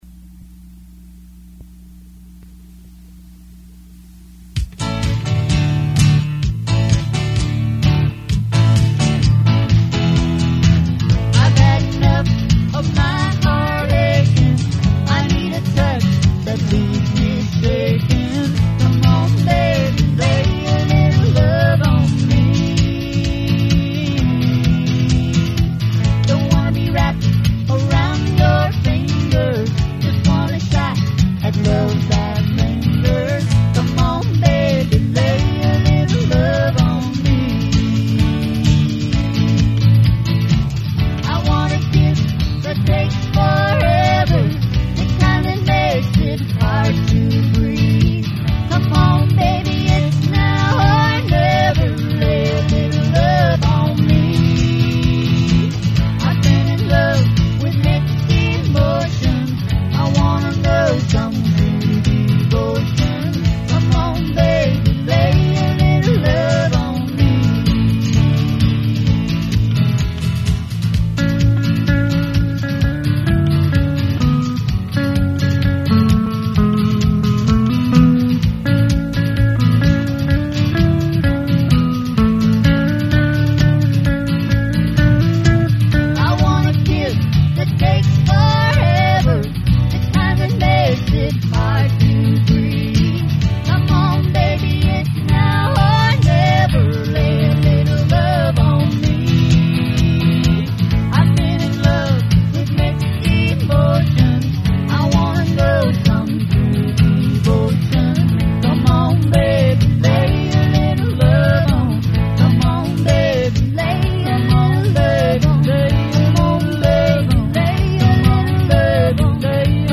4 Track Demo (early)